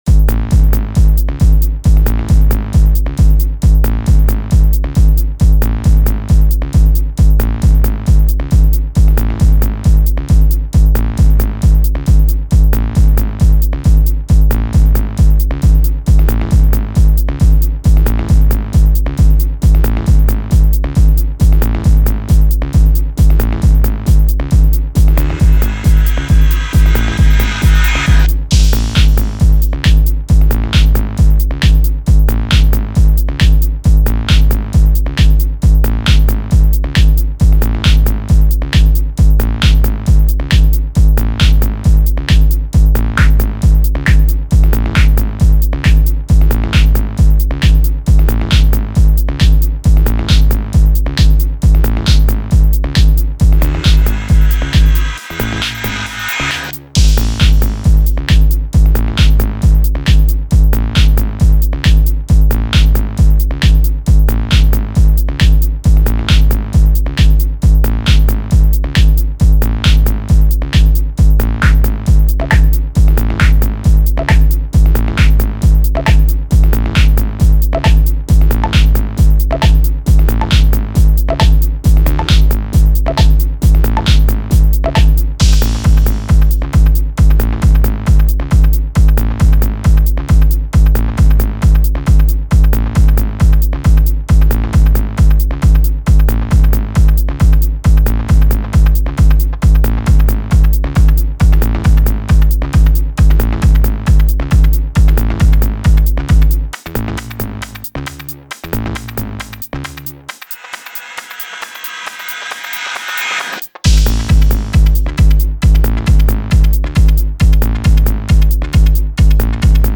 Four four, glitchy, racey, beats music.
Drum kit, bass, fx.